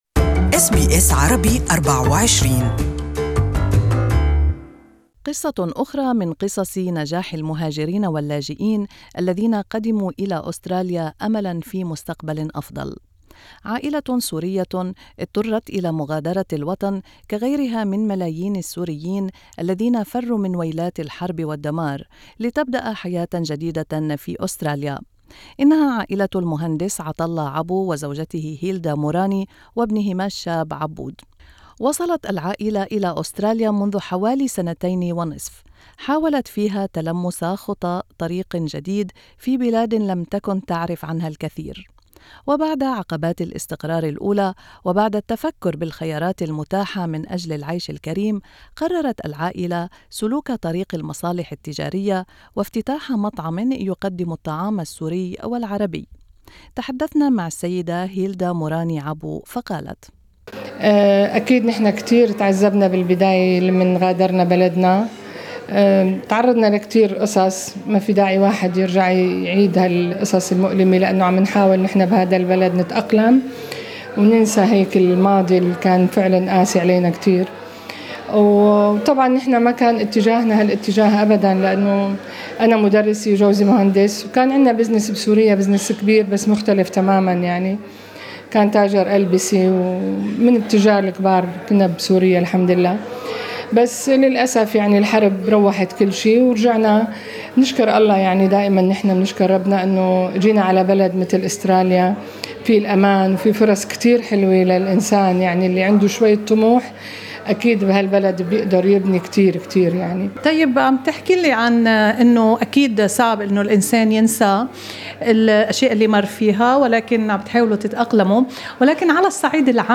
استمعوا إلى اللقاء كاملا تحت الشريط الصوتي.